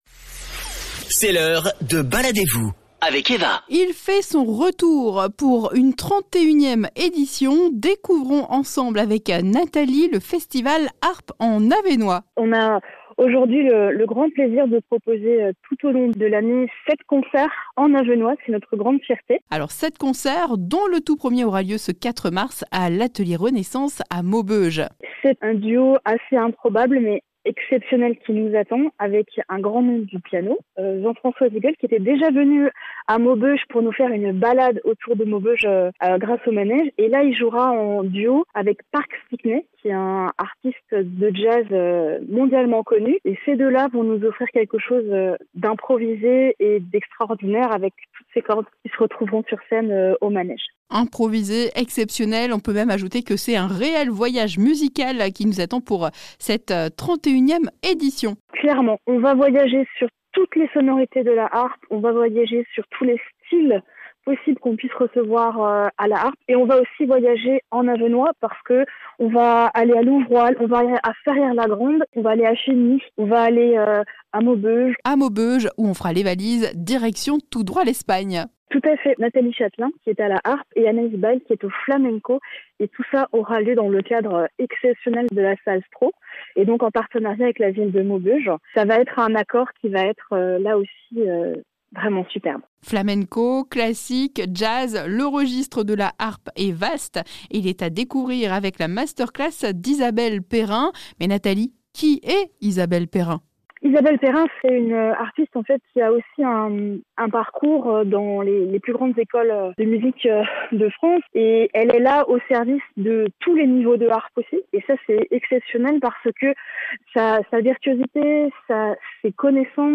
Harpes, contrebasse, percussions